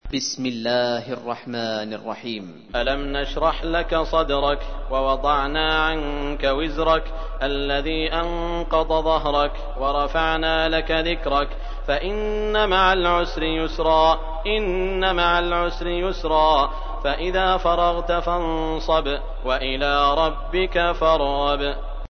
تحميل : 94. سورة الشرح / القارئ سعود الشريم / القرآن الكريم / موقع يا حسين